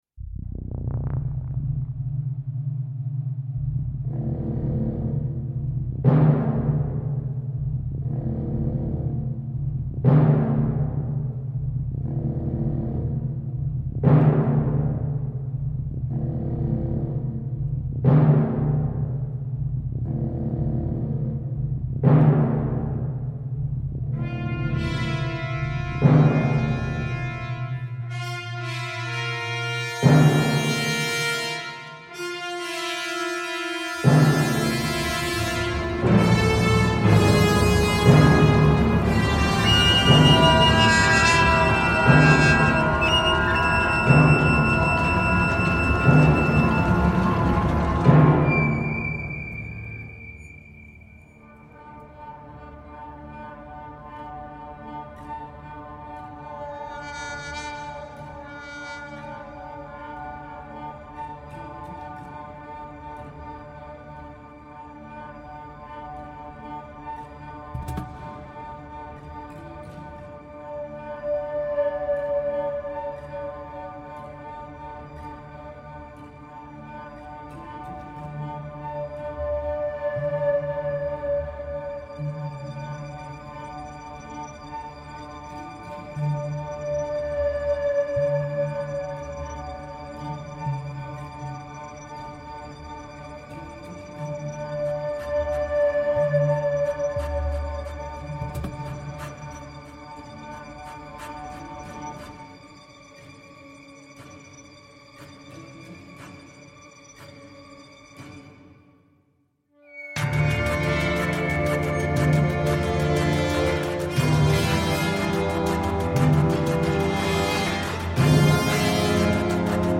percutant pour orchestre (et ponctuellement chœur)